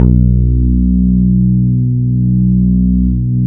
GROOVE BASS.wav